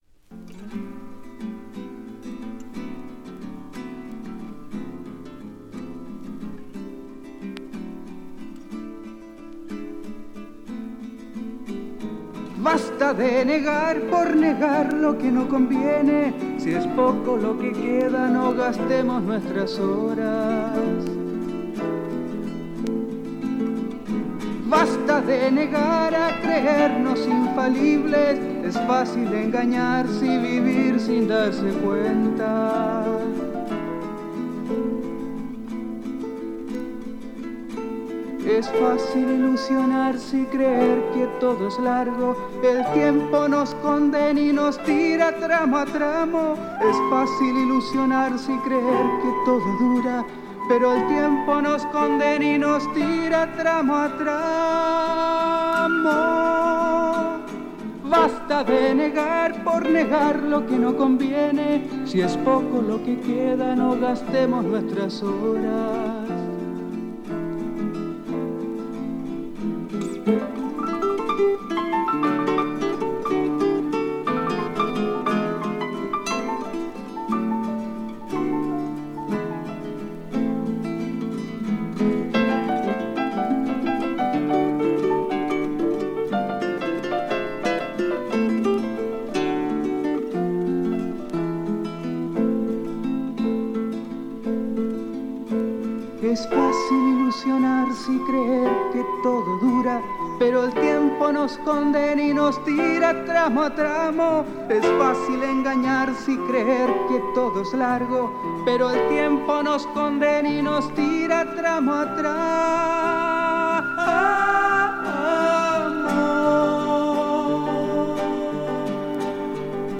フォルクローレの感覚を色濃く残した極上アシッド・フォーク！